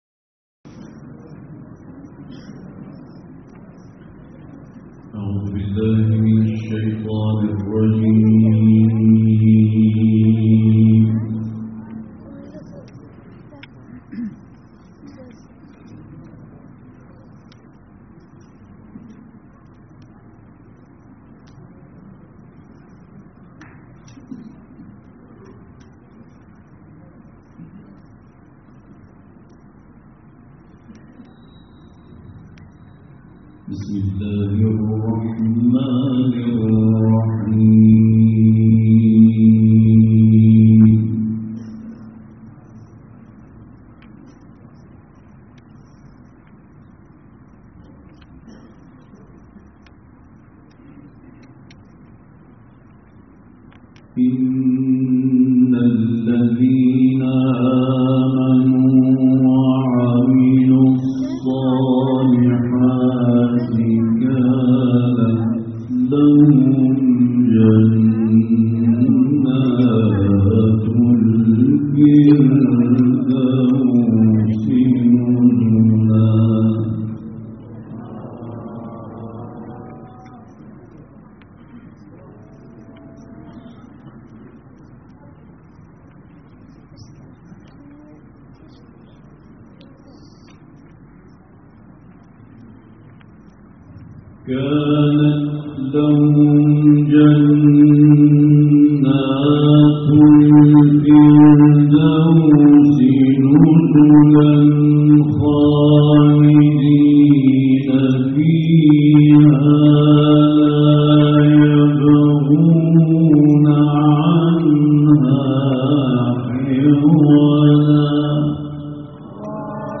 دانلود قرائت سوره های کهف آیات 107 تا 110 ، سوره مریم آیات 1 تا 15 و سوره کوثر و اخلاص - استاد سعید طوسی
تلاوت-سعید-طوسی.mp3